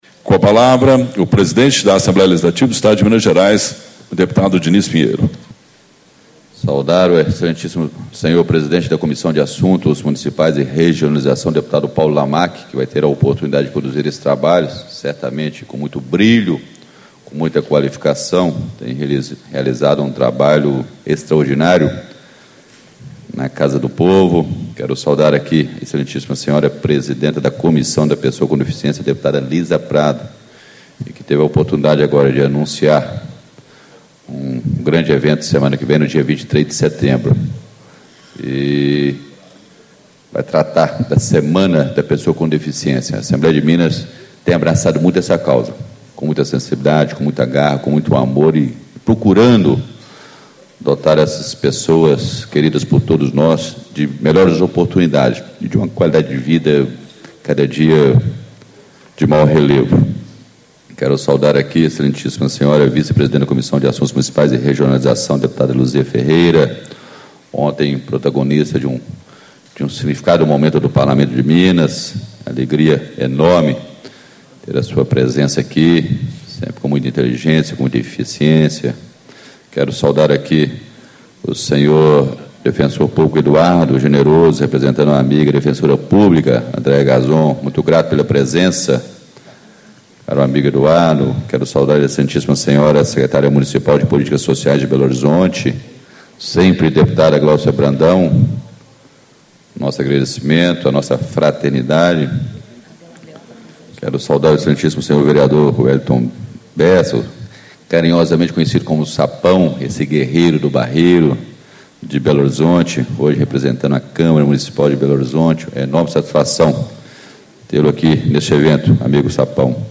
Abertura - Deputado Dinis Pinheiro, PSDB - Presidente da Assembleia Legislativa do Estado de Minas Gerais
Discursos e Palestras